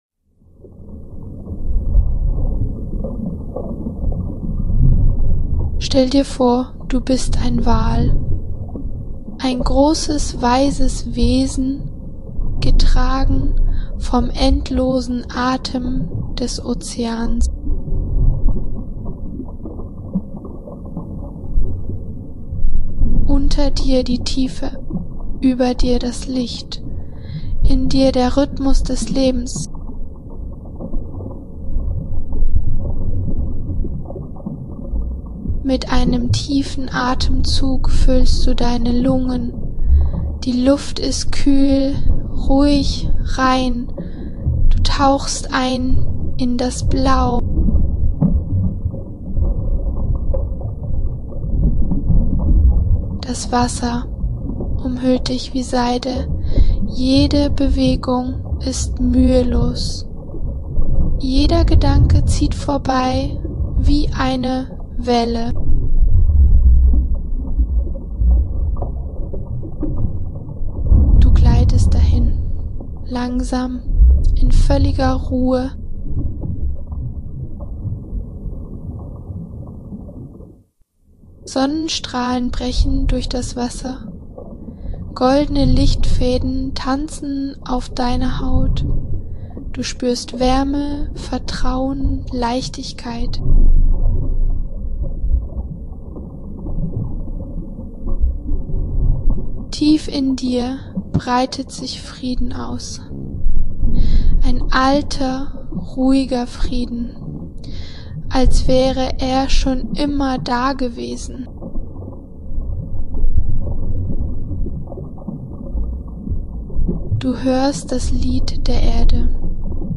Tauche ein in eine geführte Meditation, bei der du als majestätischer Wal durch unendliche, türkisfarbene Weltmeere gleitest. Sanfte Sonnenstrahlen, sphärische Klänge und der Rhythmus des Ozeans führen dich in tiefe Mindfulness – positiv, ermutigend und voller Vertrauen ins Leben.